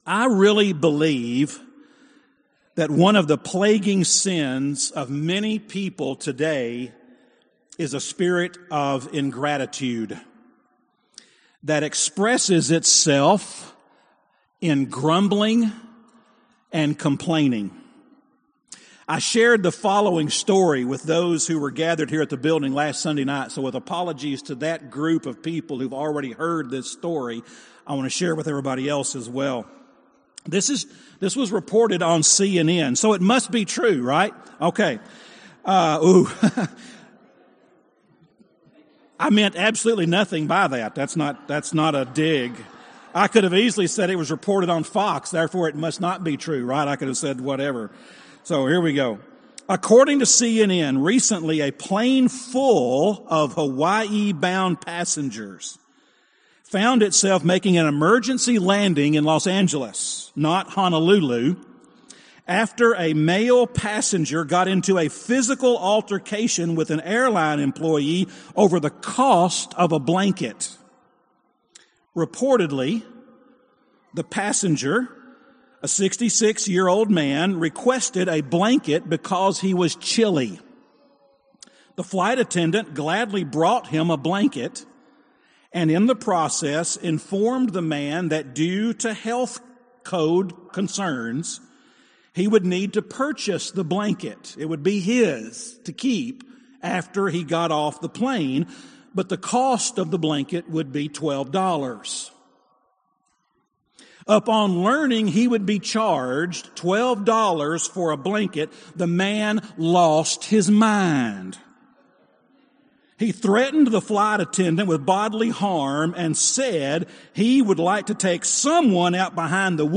University Church of Christ Sermons